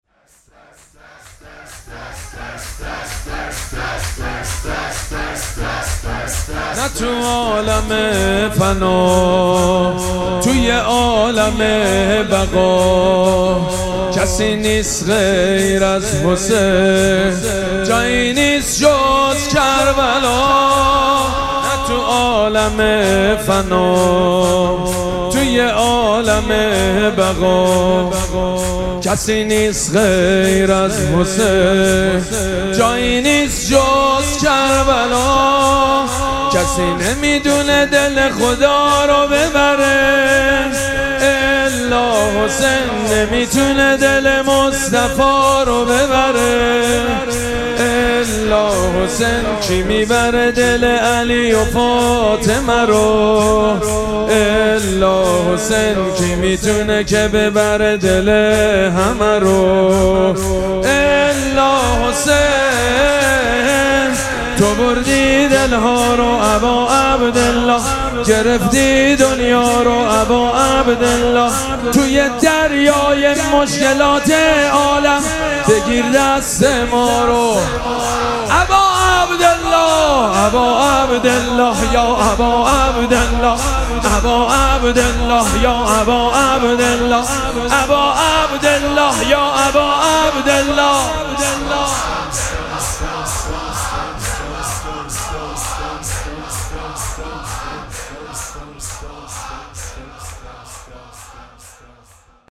مراسم مناجات شب بیست و سوم ماه مبارک رمضان
شور
مداح
حاج سید مجید بنی فاطمه